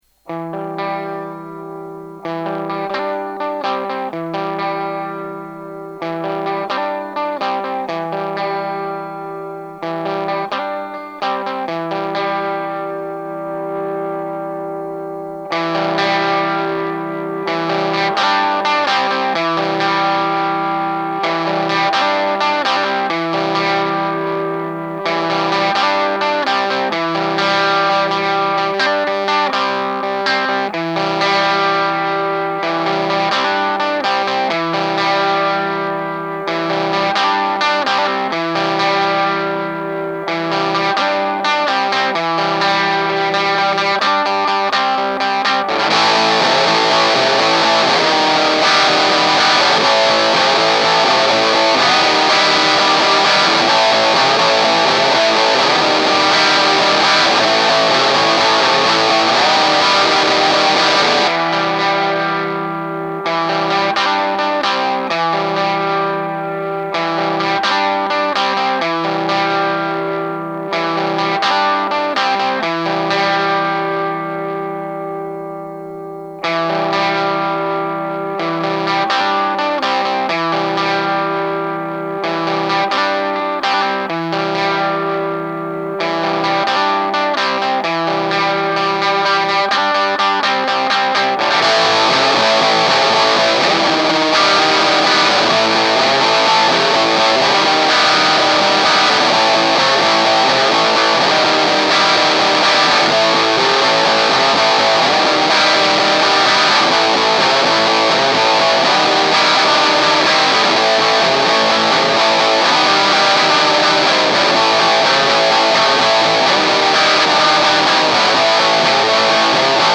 EDIT : Pour les oreilles, un petit sample comparatif : d'un côté, nous avons une prise de son façon "débutant home-studiste", avec un cab 1x8" ouvert en Eminence 875L repris par un Sennheiser e606 décentré de quelques centimètres par rapport au centre du HP ; de l'autre, le même morceau, joué avec le même préampli et la même gratte, en direct dans le PC avec Logic 9 et Recabinet en simu HP :
A noter que plusieurs mois séparent les 2 prises, donc les réglages sont globalement identiques, mais ont pu changer un peu, ce n'est pas un test scientifique, mais ça donne une idée du résultat possible quand on n'y connait rien (et même moins)
sample_mix_mic.mp3